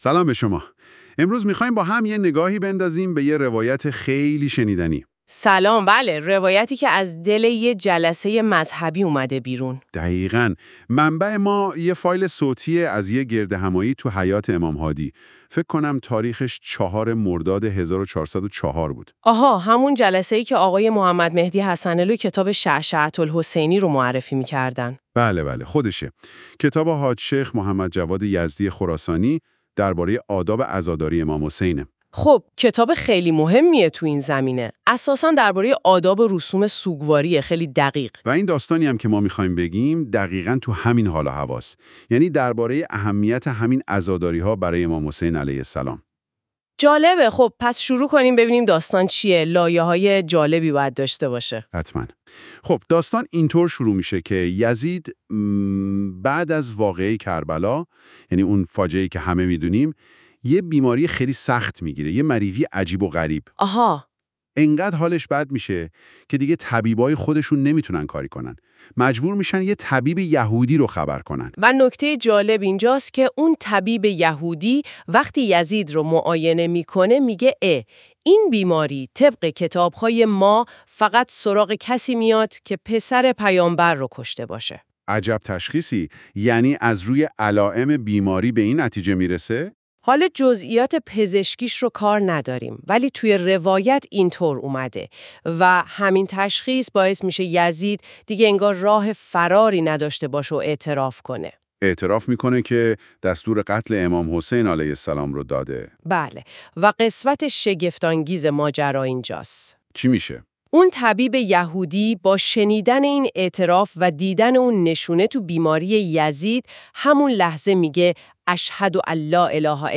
[با توجه به اینکه پادکست توسط هوش مصنوعی تولید می شود، ممکن است برخی از کلمات اشتباه تلفظ شود؛ همچنین ممکن است برخی مباحث حلقه در پادکست ذکر نشده و یا مطالبی خارج از حلقه در آن آورده شده باشد]